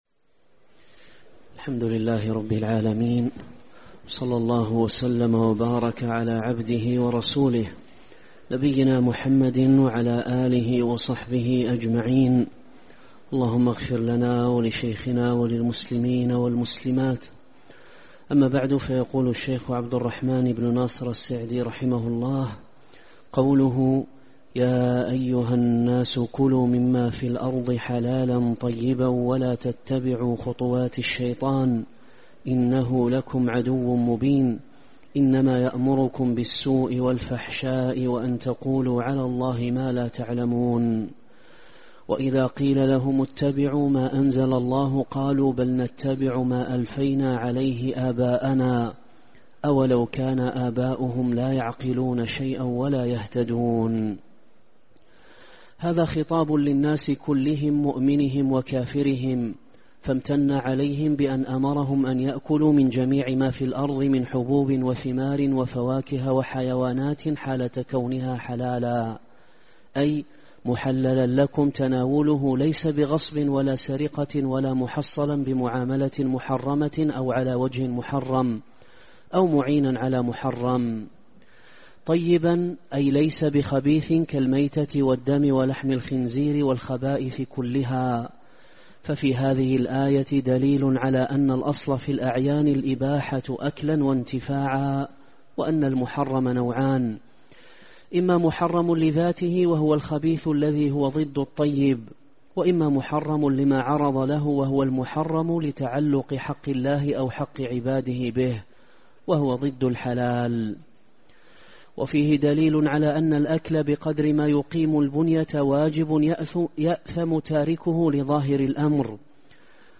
الدرس 91